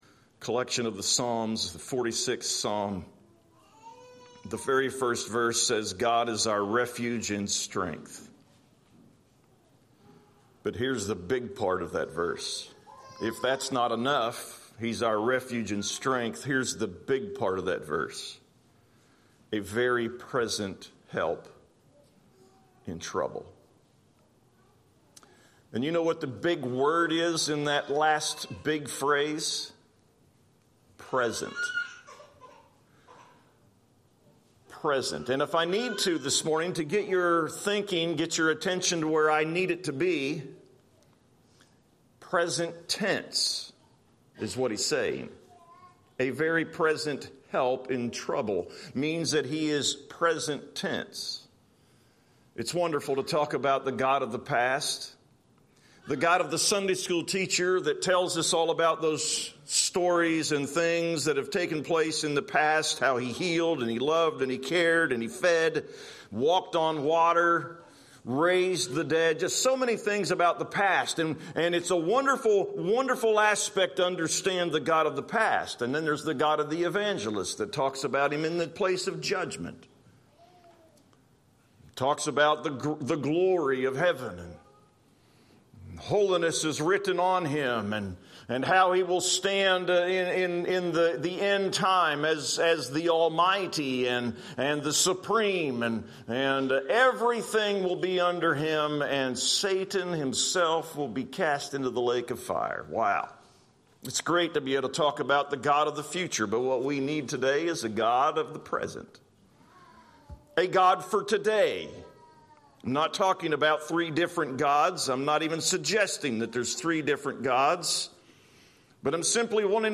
2025-11-16-am-sermon.mp3